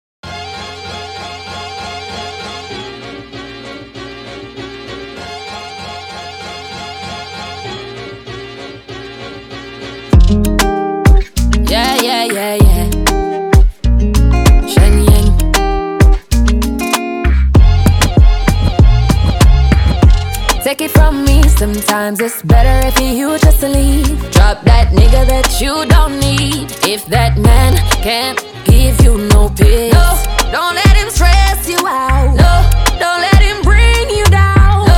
Жанр: Поп музыка / Регги
Modern Dancehall, Reggae, Pop